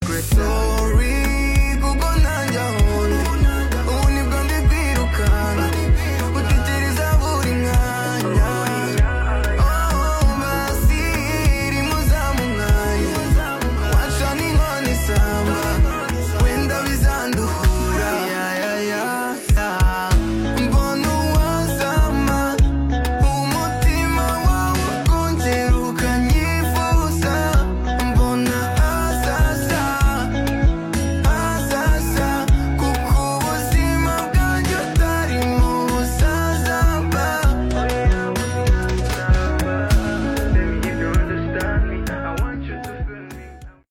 heart-warming single
feel-good energy and irresistible rhythm